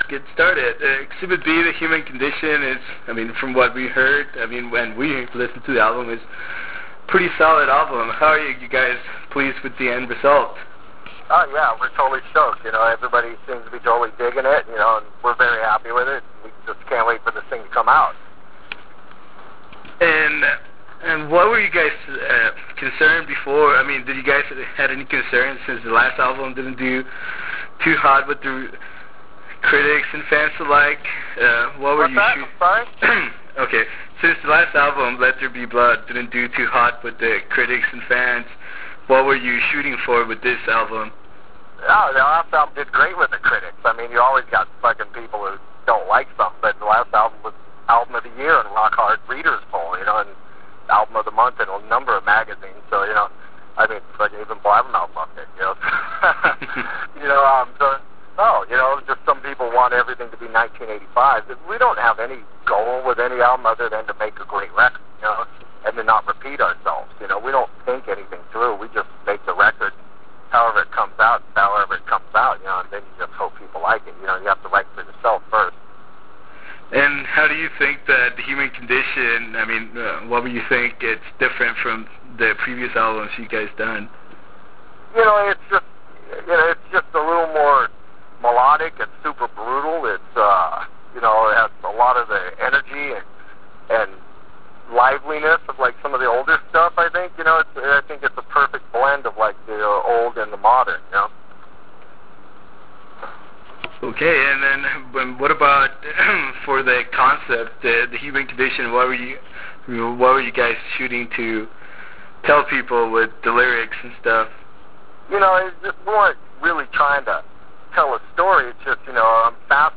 Interview with Gary Holt - Exodus - Exhibit B: The Human Condition